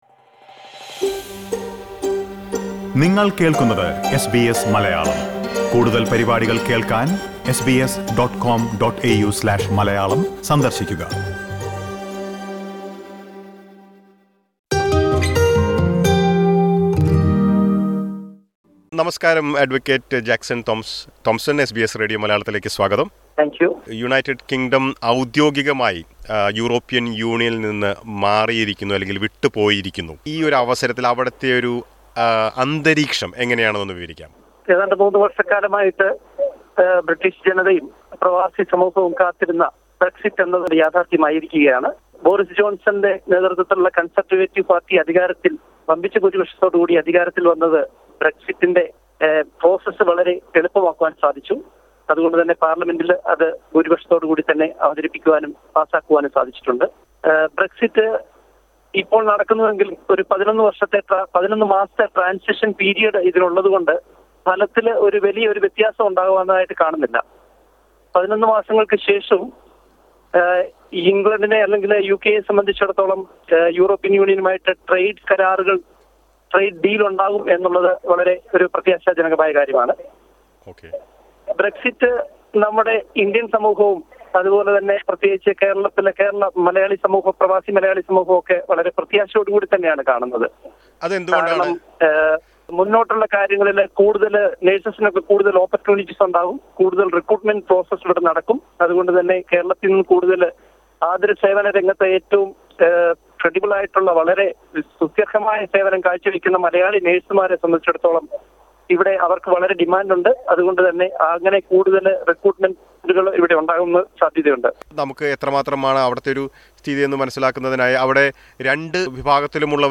What do Indian migrants feel after Brexit. SBS Malayalam speaks to some malayalees in the UK.